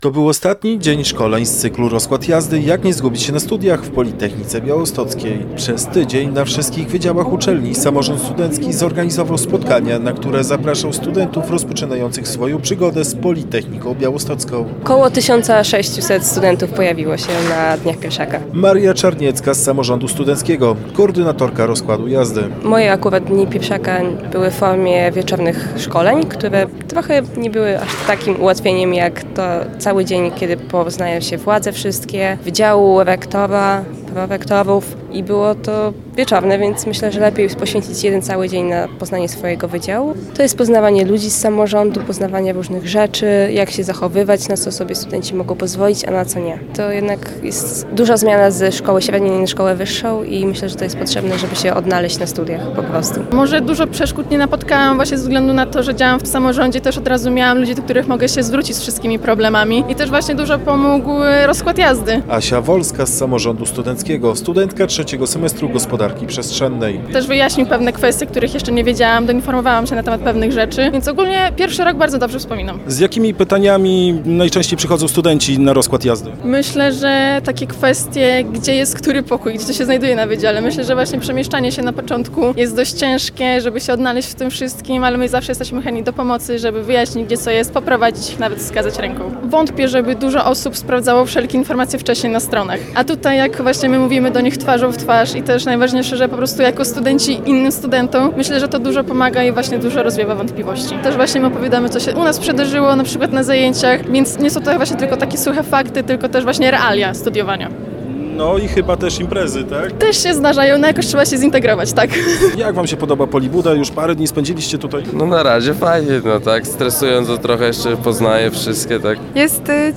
Posłuchaj co o „Rozkładzie Jazdy” mówią organizatorzy z samorządu studentów PB: